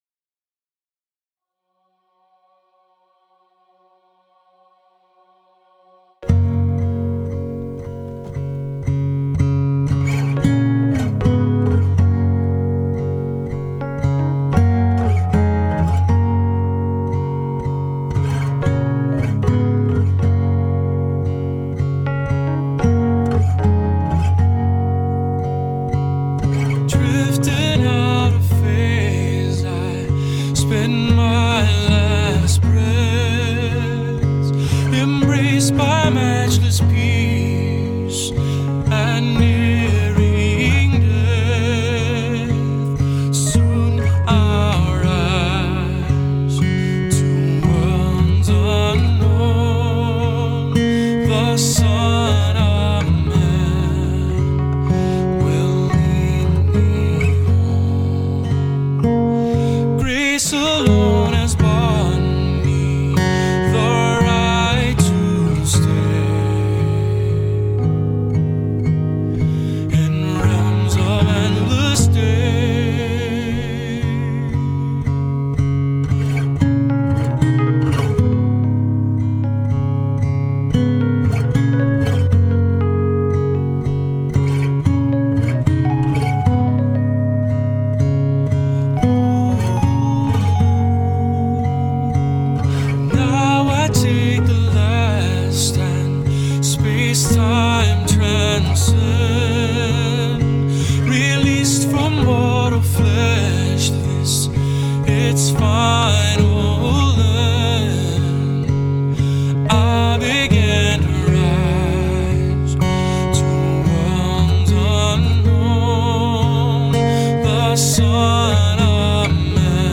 dance/electronic
Particularly cheery, however.